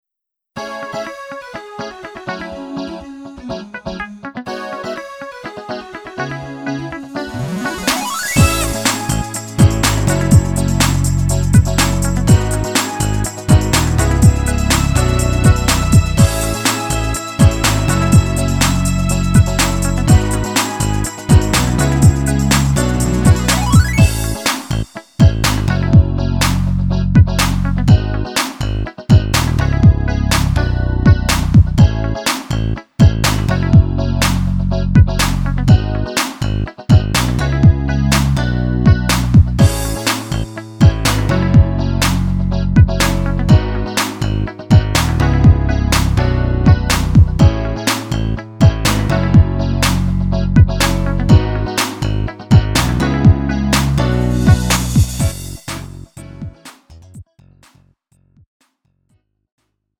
음정 -1키 3:39
장르 가요 구분 Lite MR